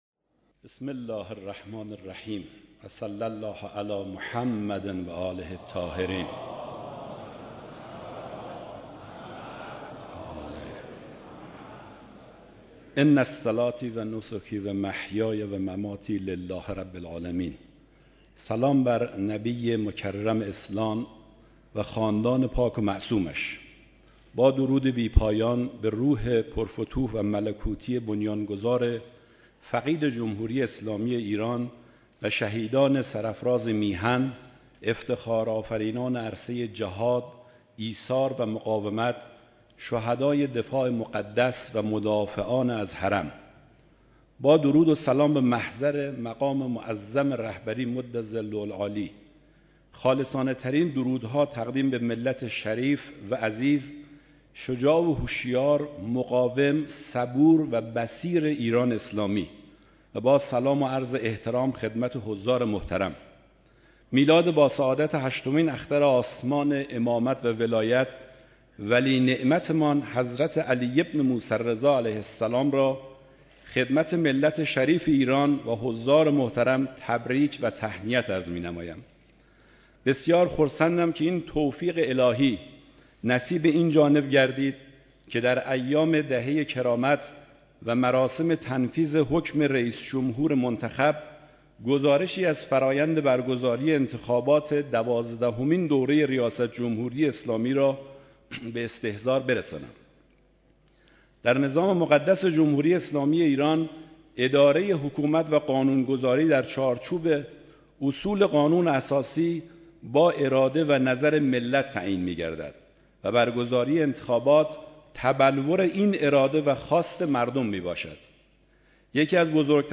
مراسم تنفیذ حکم ریاست جمهوریِ حجت‌الاسلام دکتر روحانی
ارائه گزارش جناب آقای رحمانی فضلی وزیر کشور